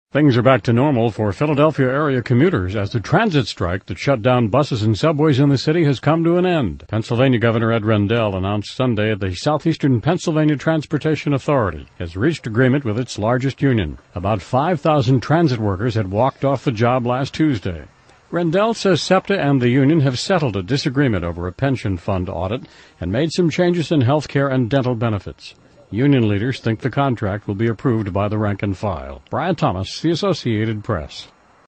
AP美联社一分钟新闻(2009-11-10) 听力文件下载—在线英语听力室